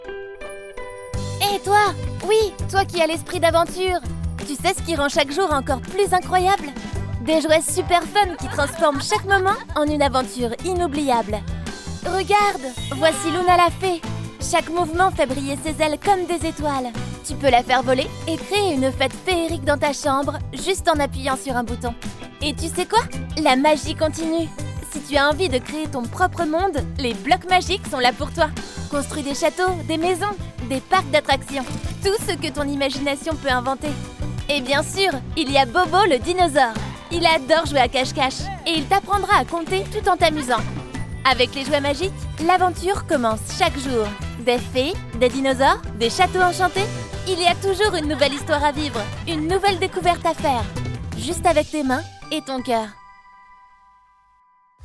Voix off en Français
Jeune, Naturelle, Amicale, Corporative